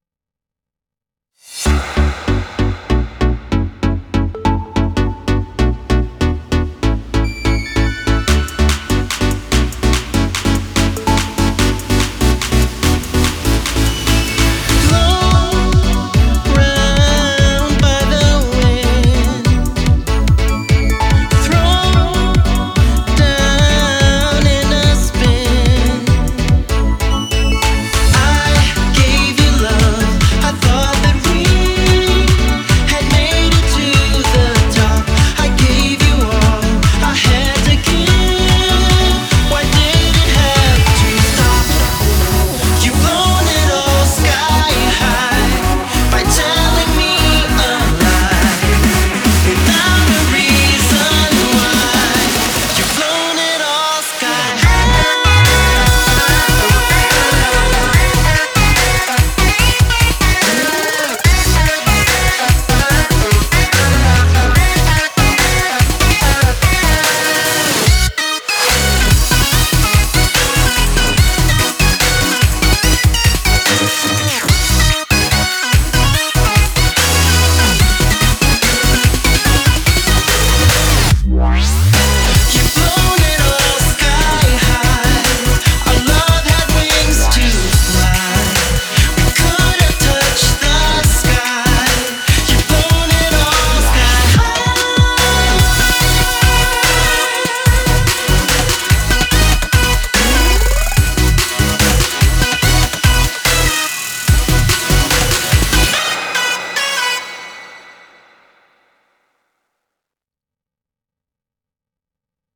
BPM145
Audio: From official simfile